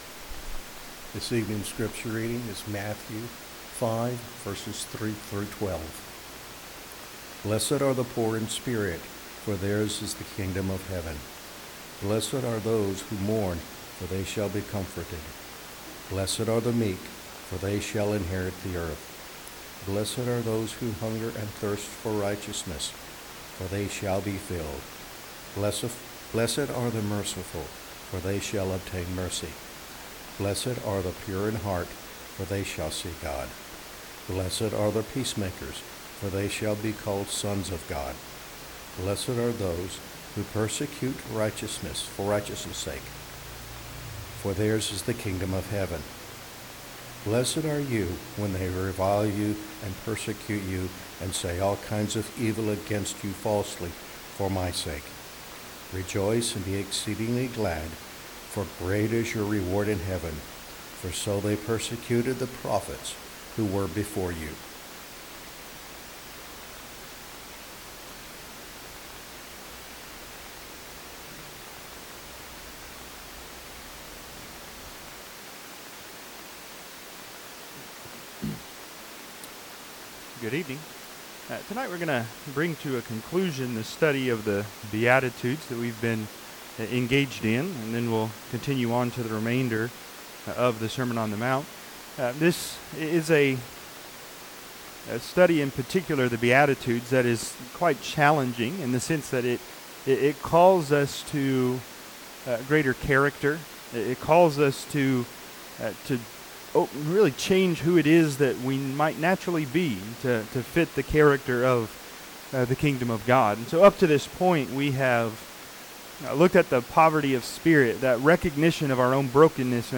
Matthew 5:3-12 Service Type: Sunday PM Topics